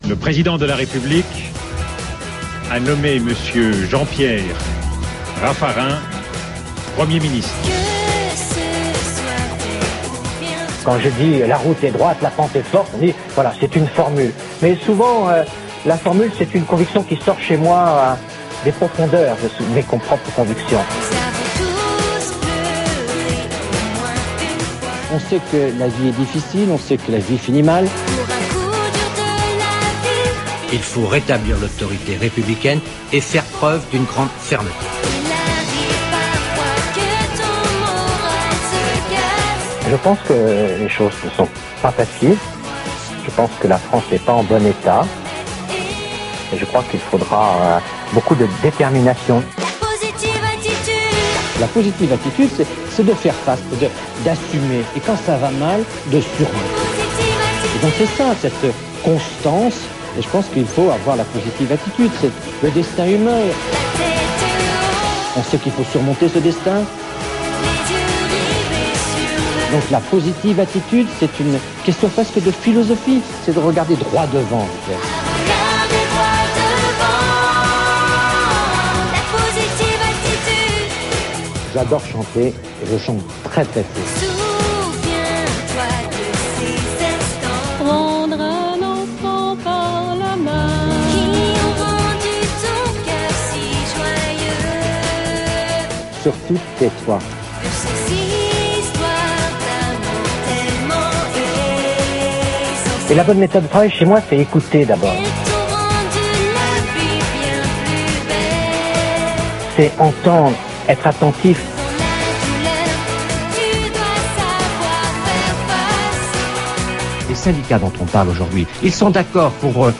Les Mixs et les bidouillages sonores des oreilles... ce sont des délires de quelques minutes.